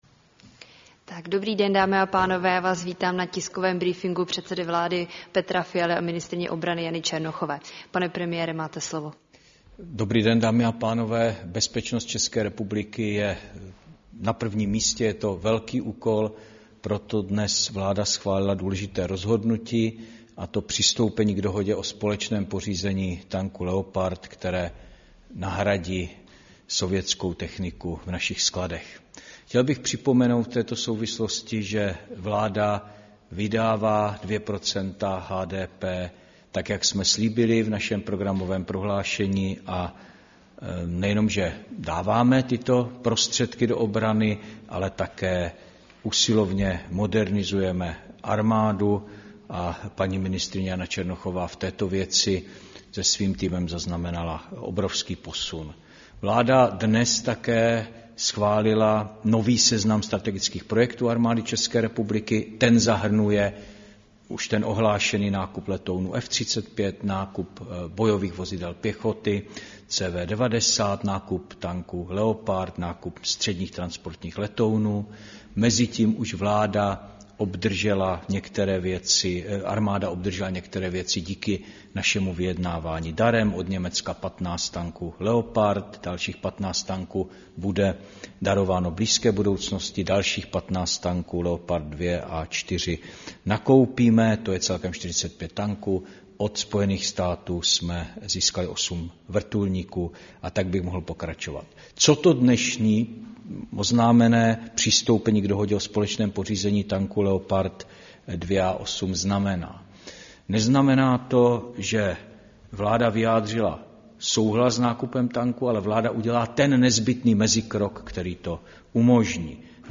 Tisková konference premiéra Petra Fialy a ministryně obrany Jany Černochové, 12. června 2024